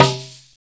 SI2 BONK.wav